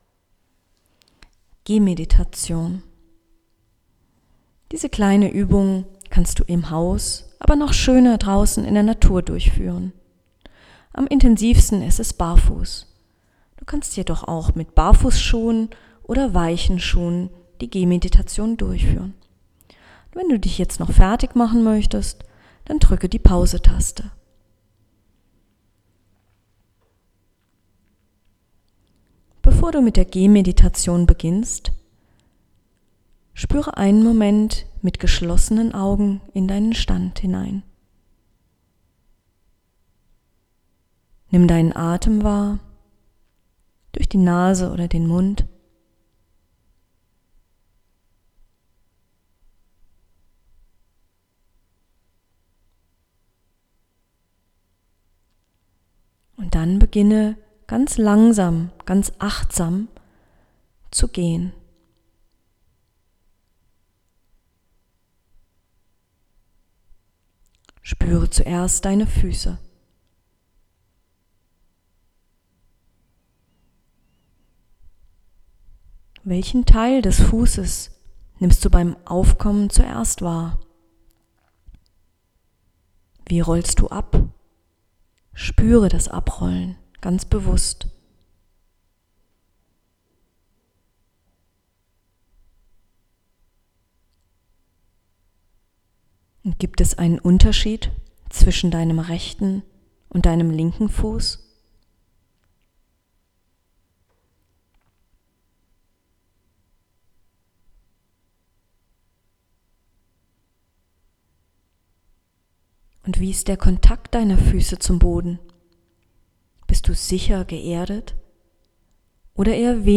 Weiter unten findest du sie auch noch mal als gesprochen Meditation im MP3-Format.
Audio Gehmediation
Gehmeditation.m4a